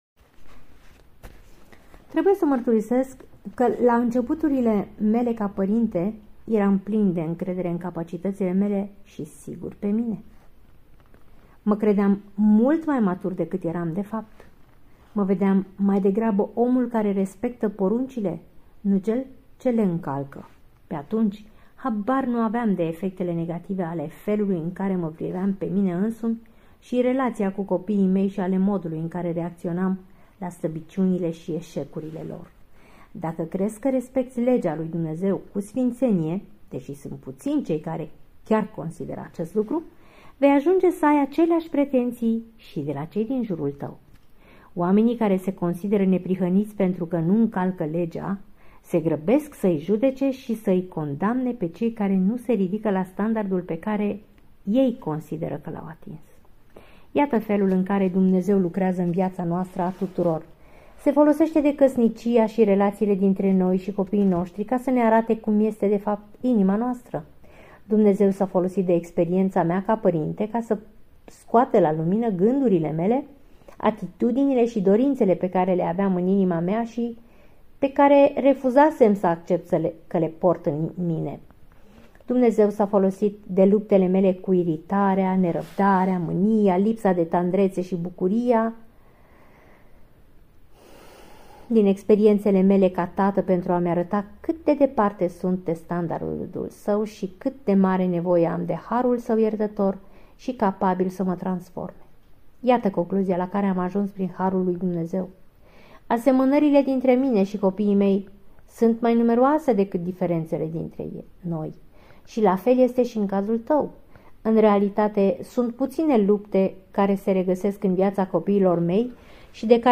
Este al doilea capitol al cărții "Pentru părinți - 14 principii care îți pot schimba radical familia" de la Paul David Tripp.